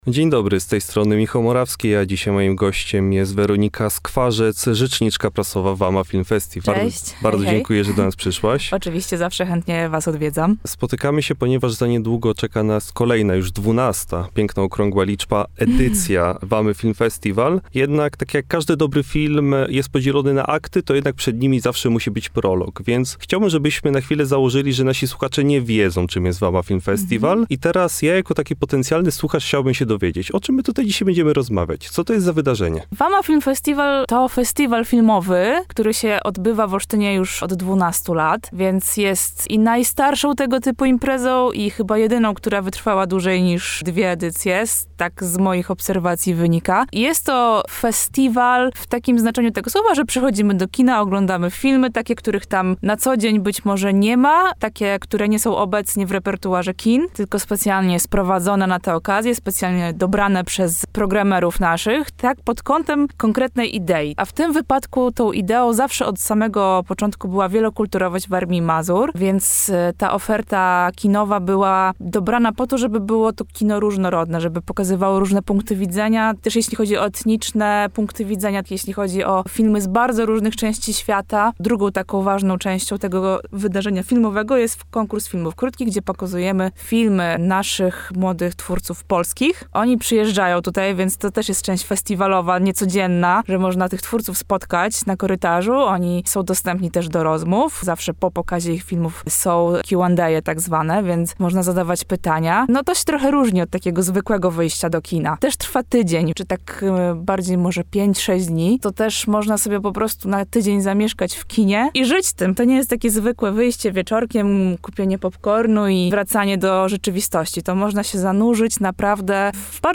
– mówiła w naszym studiu